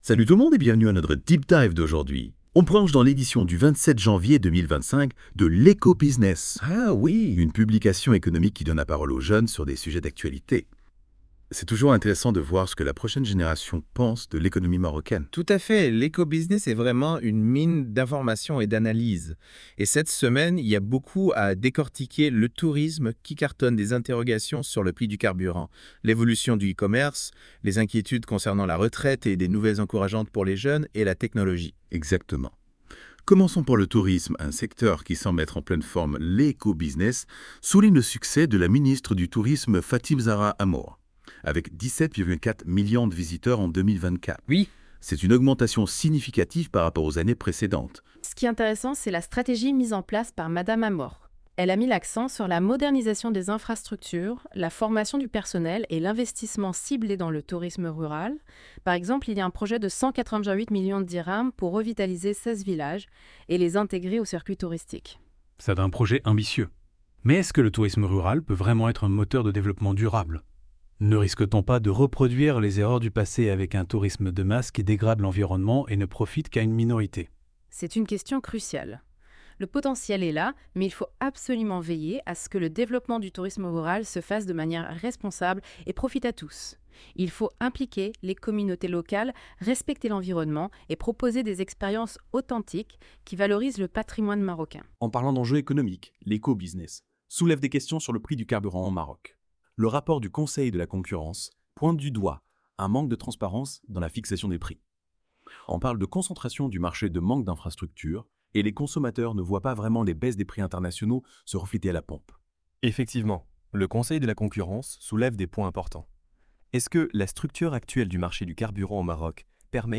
Débat des chroniqueurs de la Web Radio R212 des informations de L'Eco Business du 27 janvier 2025